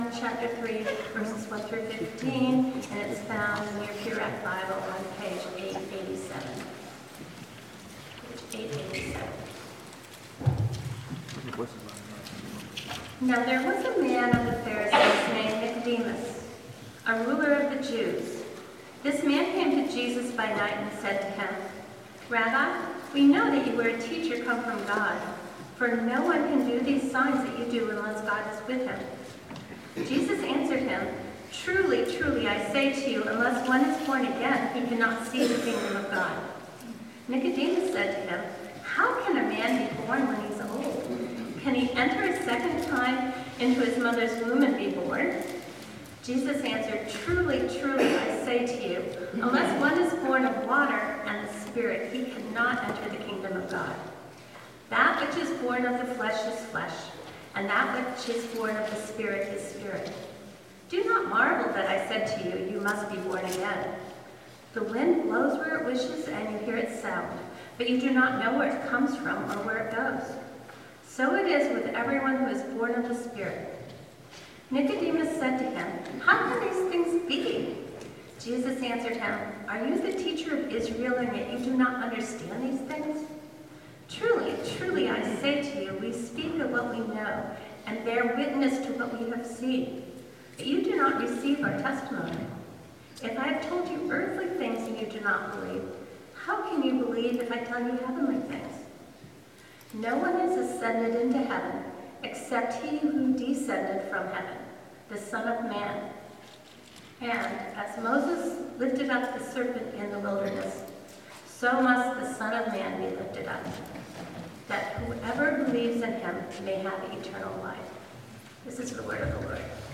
Passage: John 3:1-15 Sermon